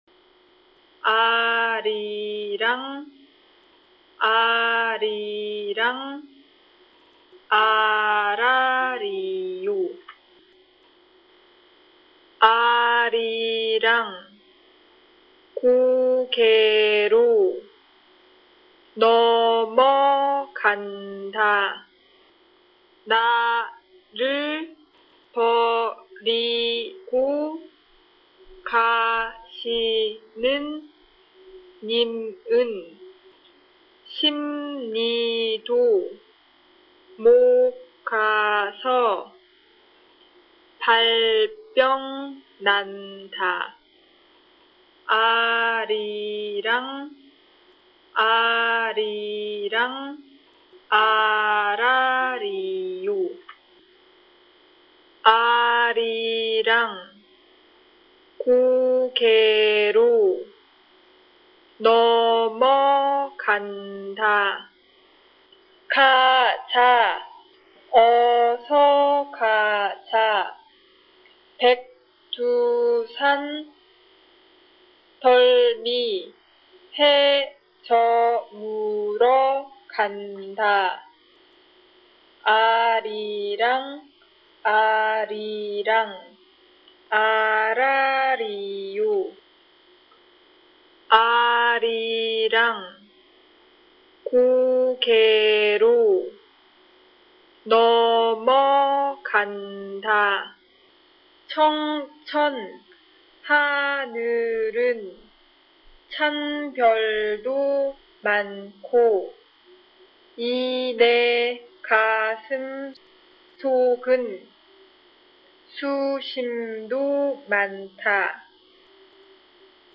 Korean Lyrics read here
arirang_korean_read.mp3